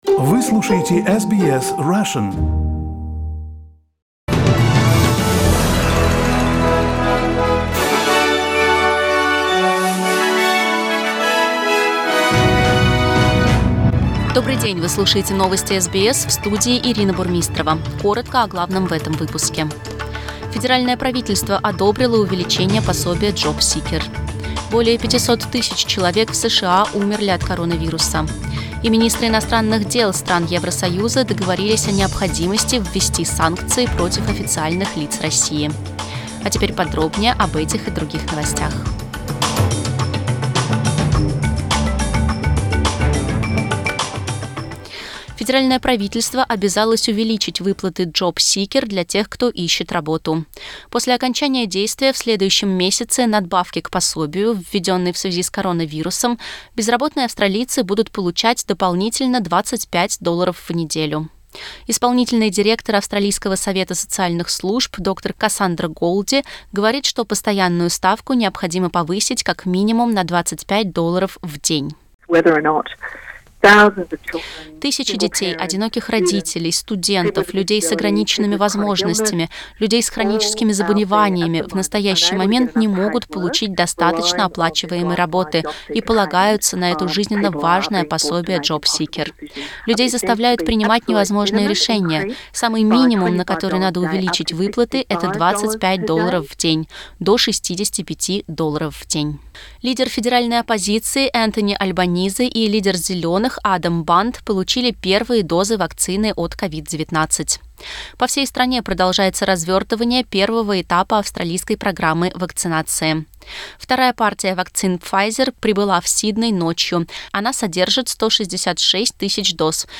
Новостной выпуск за 23 февраля